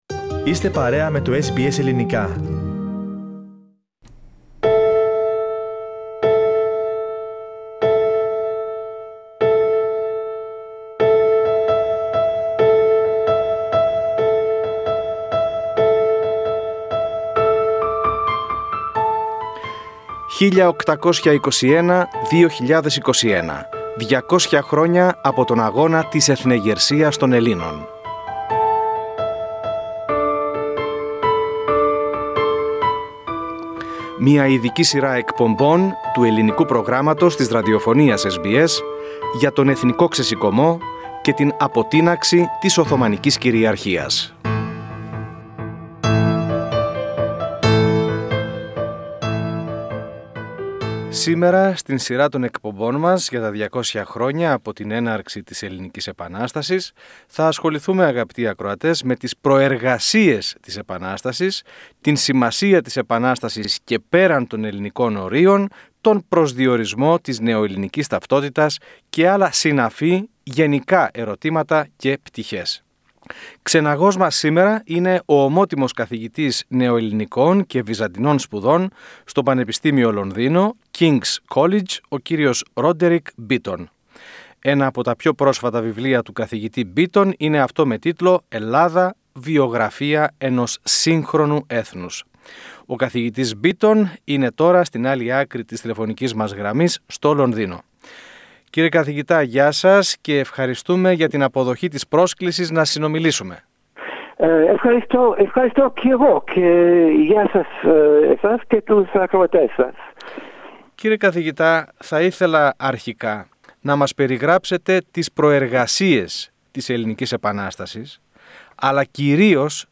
Συνομιλητής μας είναι ο ομότιμος καθηγητής Νεοελληνικών και Βυζαντινών σπουδών στο Πανεπιστήμιο Λονδίνου (King’s College) Roderick Beaton .